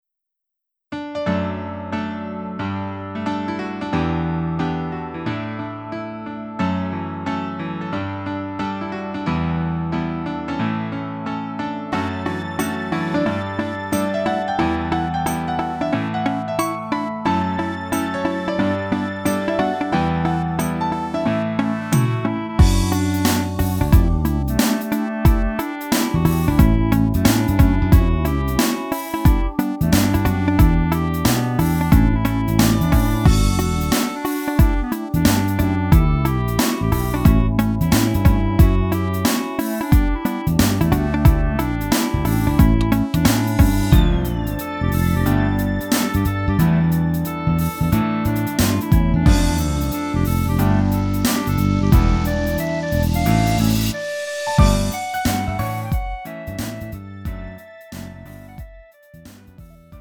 음정 -1키 3:44
장르 가요 구분 Lite MR